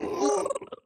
Minecraft Version Minecraft Version 25w18a Latest Release | Latest Snapshot 25w18a / assets / minecraft / sounds / mob / strider / happy3.ogg Compare With Compare With Latest Release | Latest Snapshot